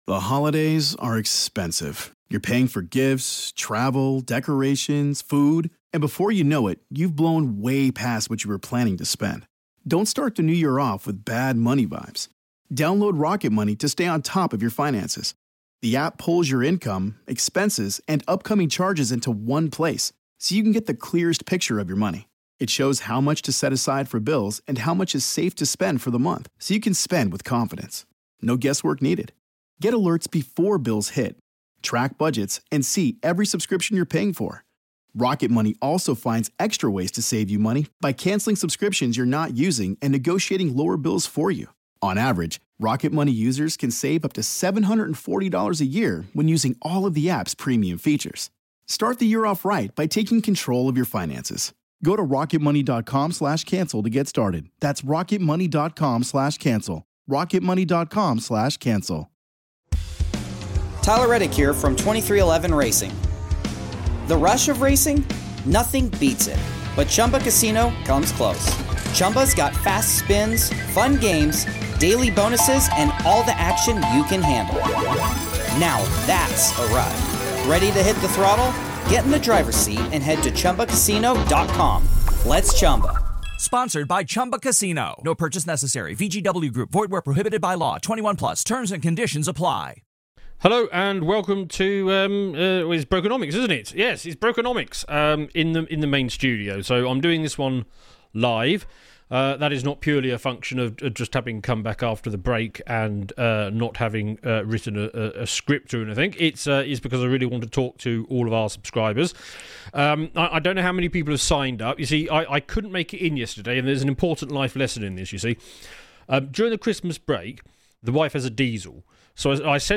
PREVIEW: Brokenomics | Live Q&A